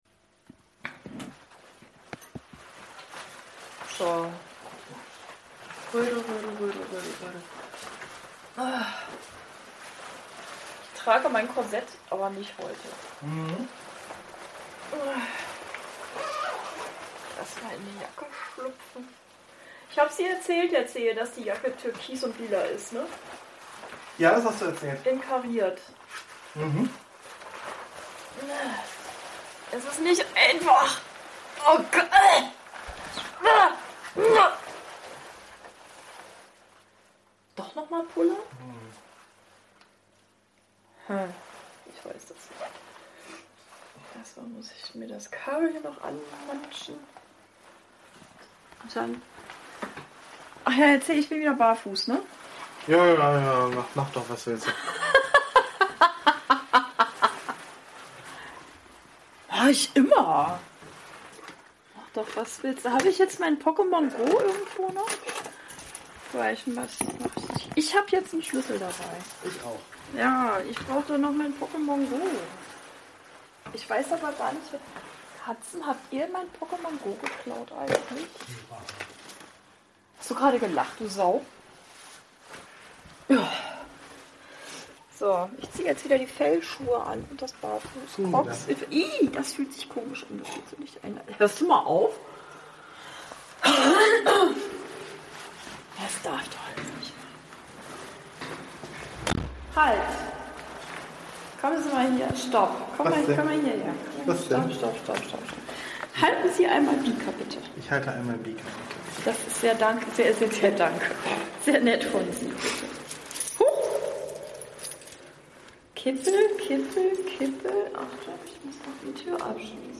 Sogar draußen!
Themen gab es keine, einfach nur eine Sabbelei unter Eheleuten.